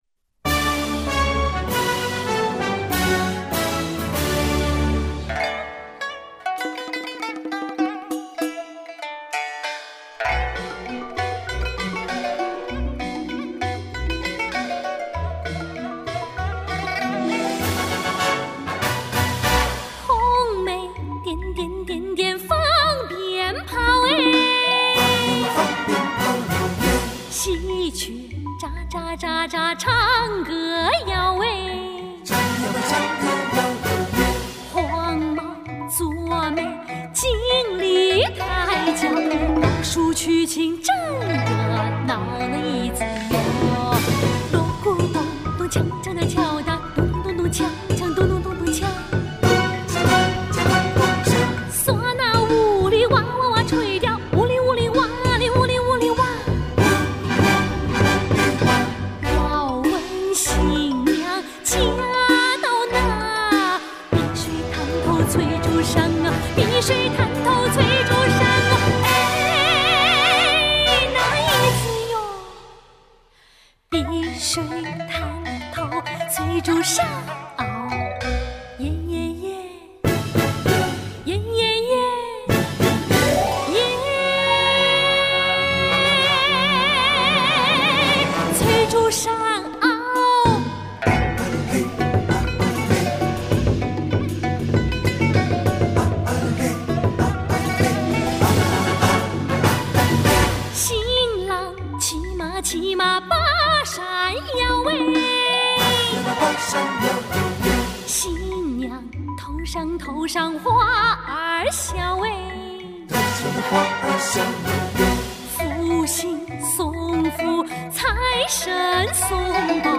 热烈欢快，风趣诙谐